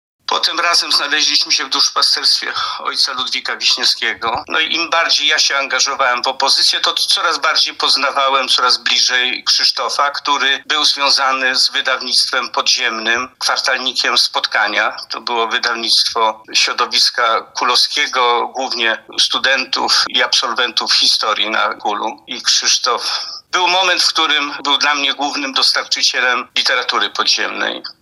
– wspomina były wojewoda lubelski Adam Cichocki.